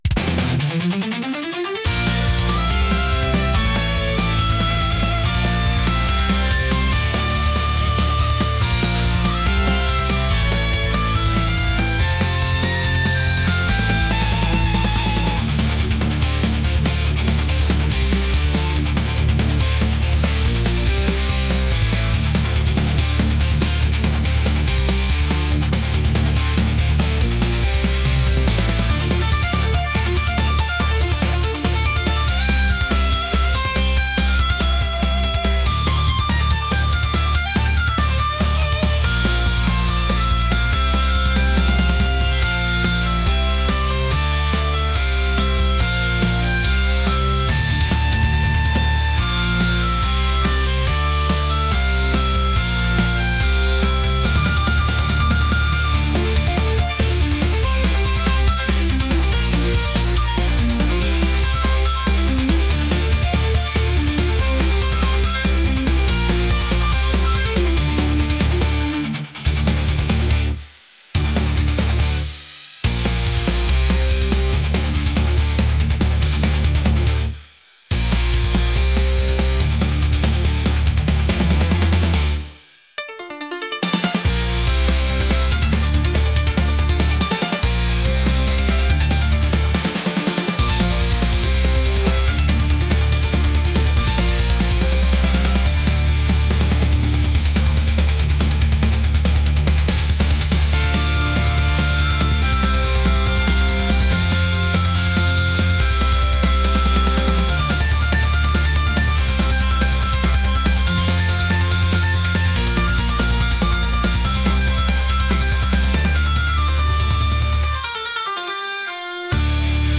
heavy | metal | intense